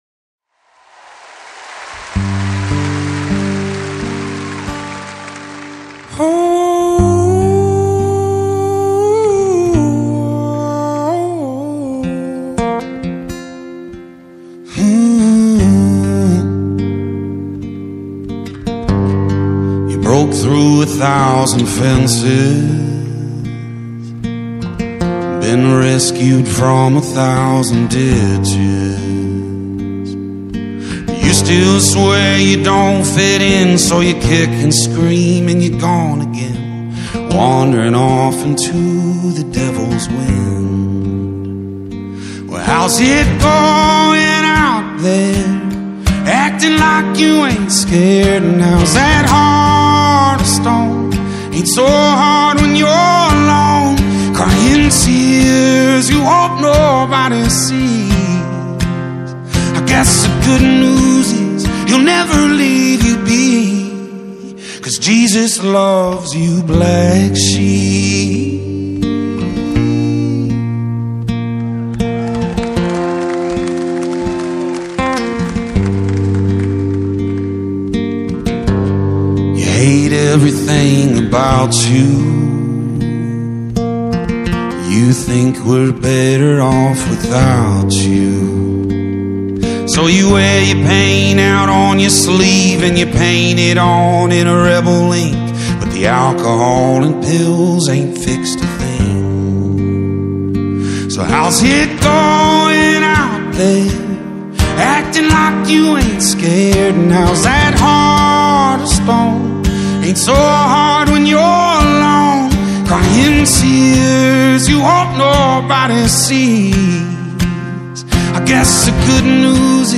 live version
soulful, stripped-back instrumentation
gravelly, jazz-inflected vocal delivery